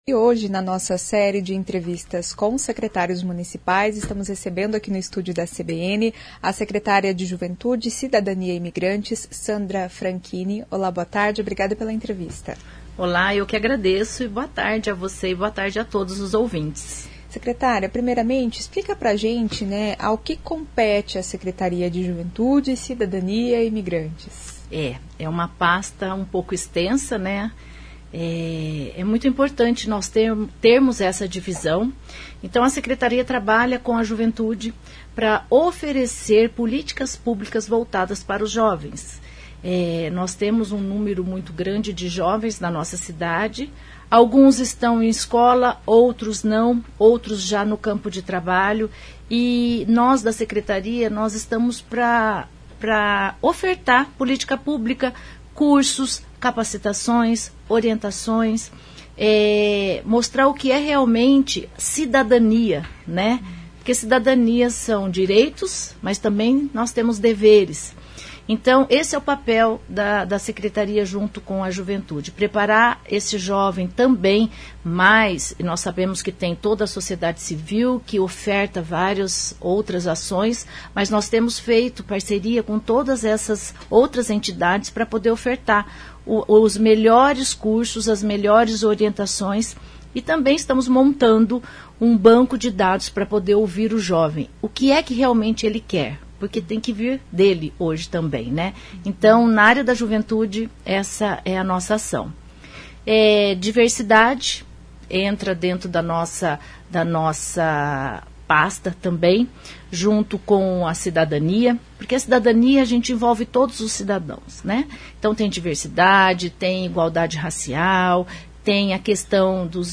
Nesta série de entrevistas a secretária da Juventude, Cidadania e Migrantes, explicou a atuação da pasta.